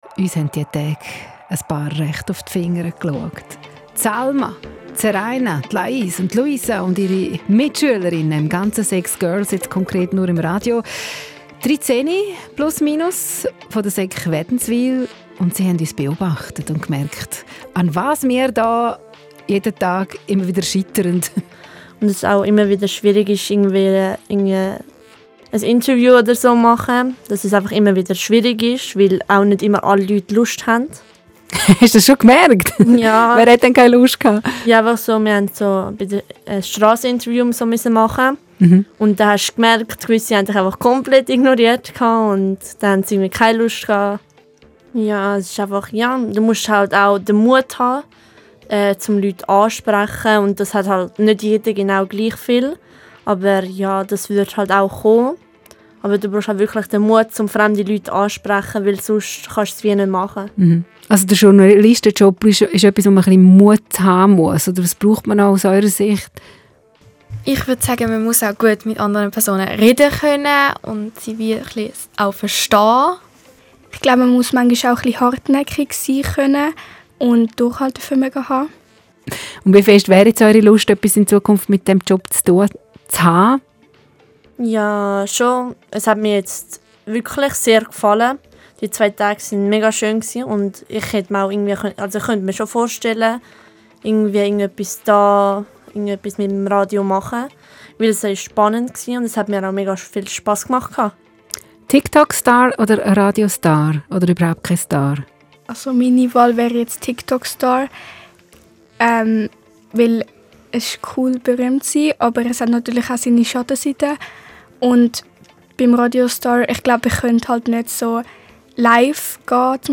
YouNews: Interview